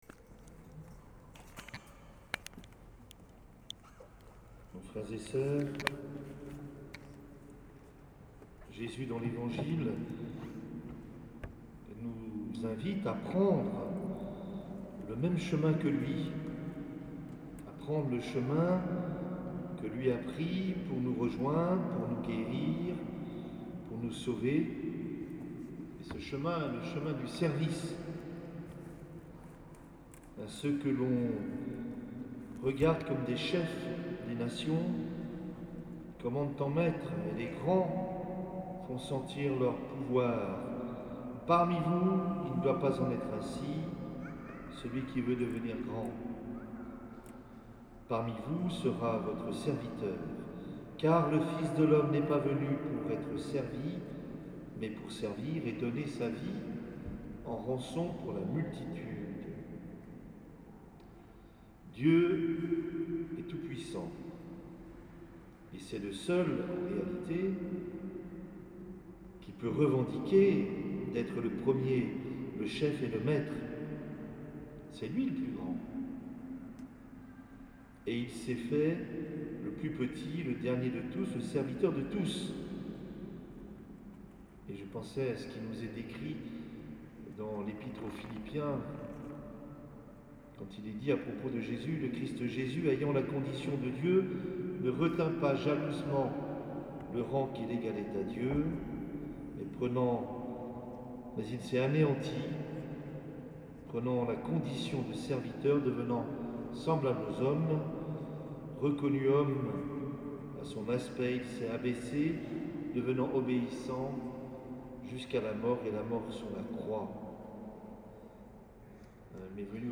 Homélie de Mgr Yves Le Saux – Cathédrale Saint-Julien Le Mans
Homélie de Mgr Yves Le Saux